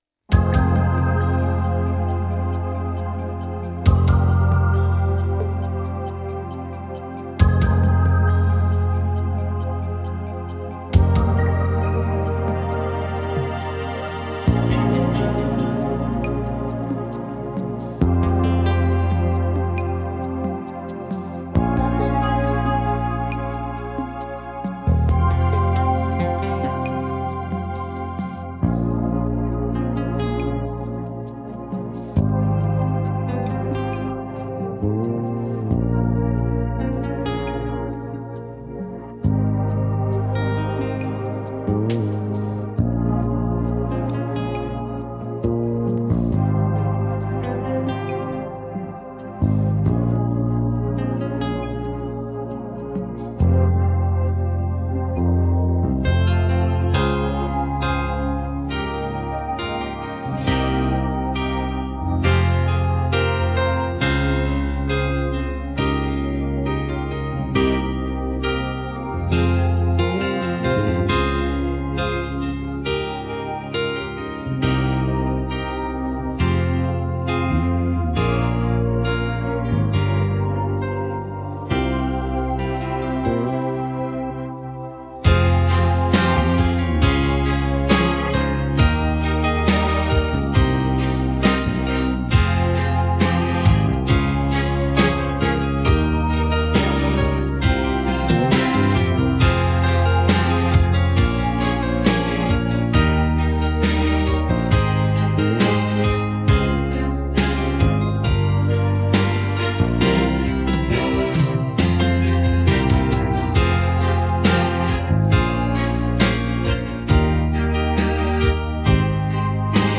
Шансон
минус